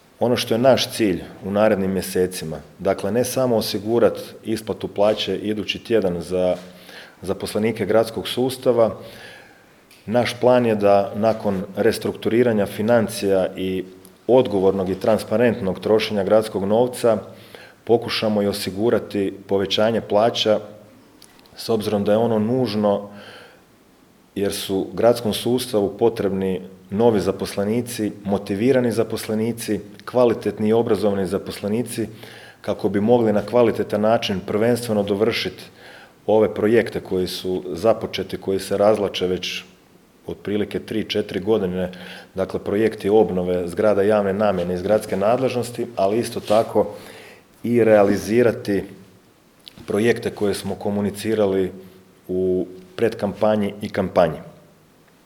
„Više od 22,3 milijuna eura duga, milijunska kreditna zaduženja, nezakonita gradnja, nestali milijuni… Zatečeno stanje u Gradu Sisku nakon odlaska bivše uprave je alarmantno. Nije riječ samo o financijama, riječ je o duboko narušenom sustavu u kojem su ugrožene osnovne funkcije grada”, istaknuo je danas na tiskovnoj konferenciji novi gradonačelnik Siska Domagoj Orlić